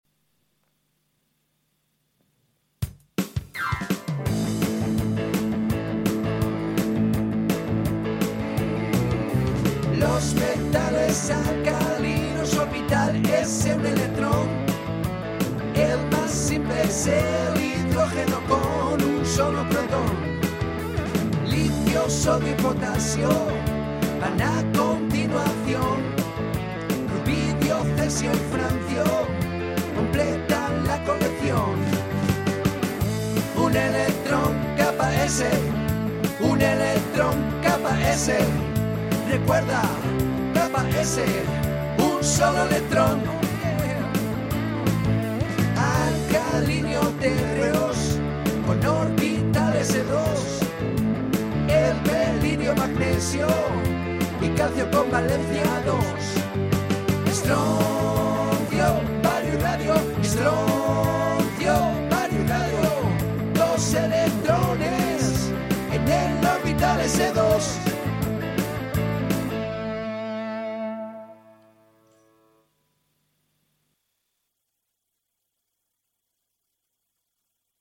Tema musical con la lista de los metales alcalinos y alcalinotérreos.